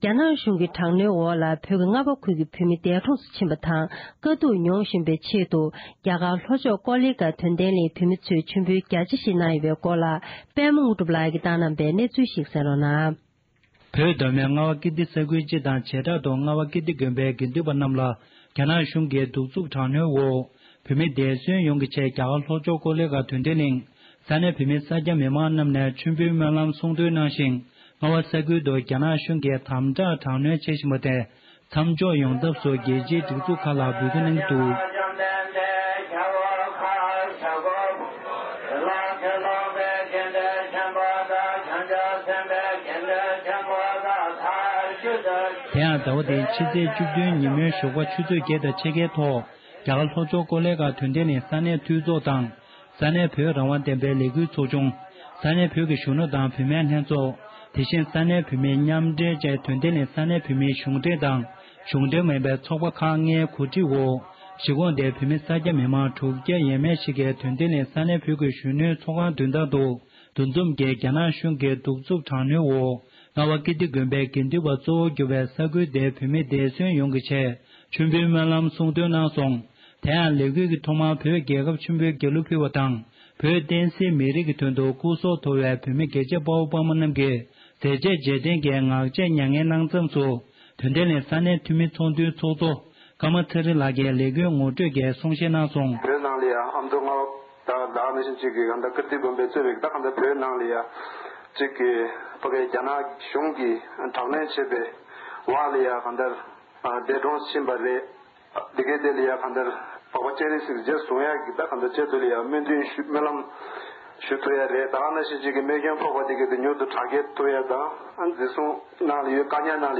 གནས་ཚུལ་བཏང་བ་ཞིག་གསན་རོགས༎